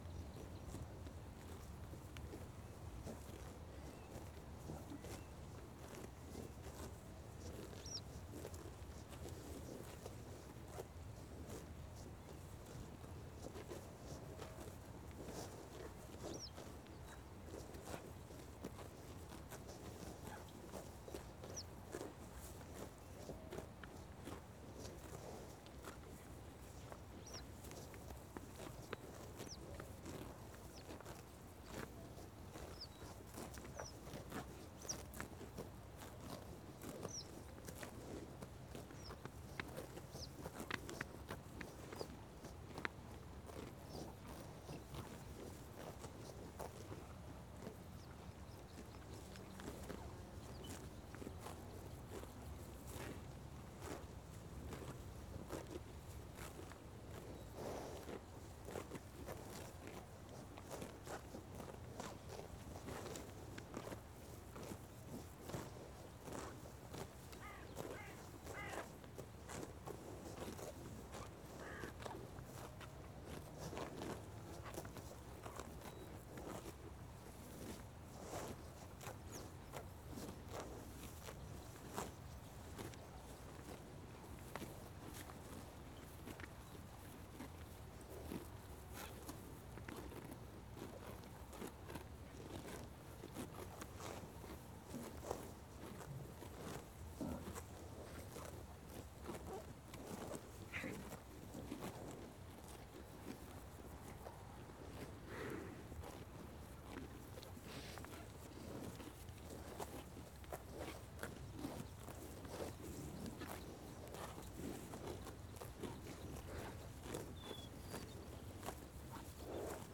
grazing cattle 130720_00
Category 🌿 Nature
ambiance ambience ambient cattle chewing country cow cows sound effect free sound royalty free Nature